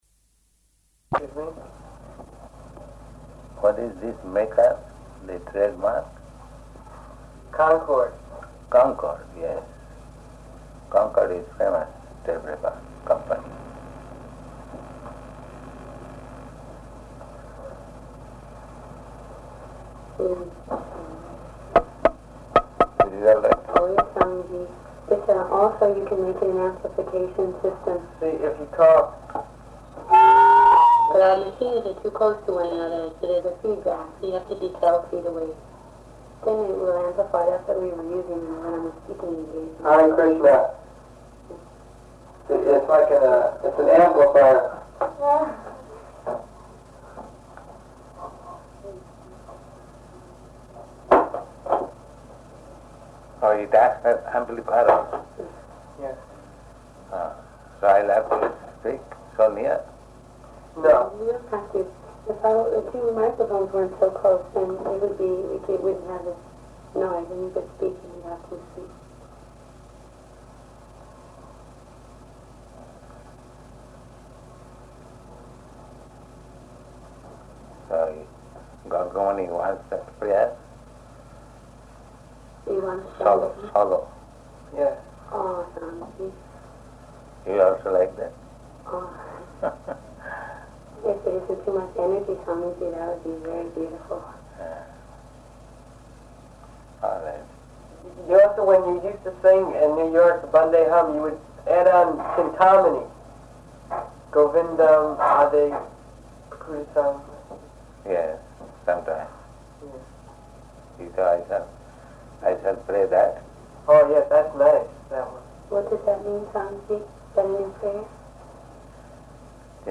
Talk in Studio